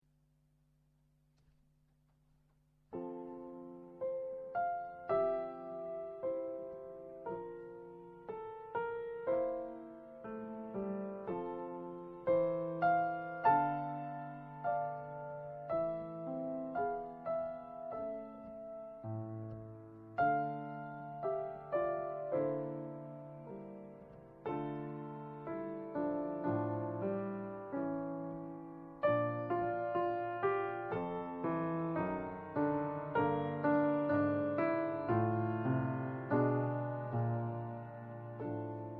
piano
- Larghetto